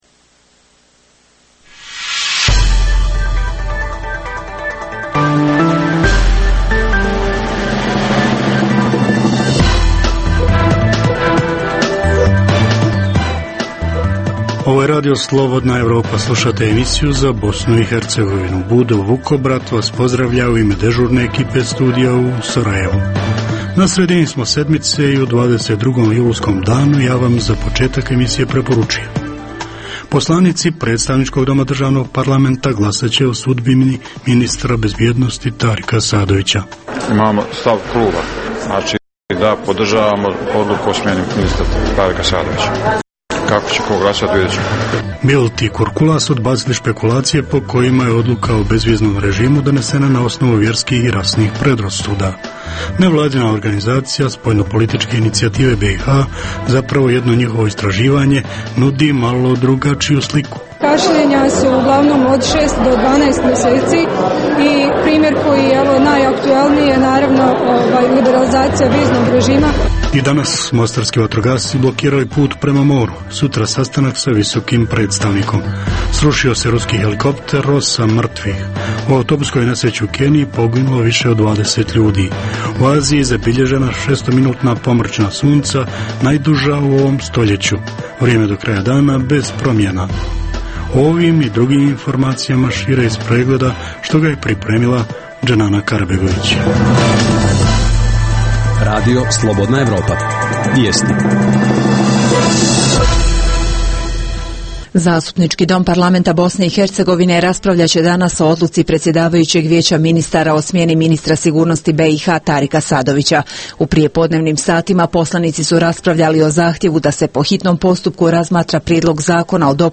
Reporteri RFE su na licu mjesta u Mostaru, gdje je u toku blokada puta prema moru, te u Banjaluci, gdje se govori o bezbjednosnoj situaciji u RS. Izvještavamo i o pojavi infekcije virusom H1N1 u Kupresu.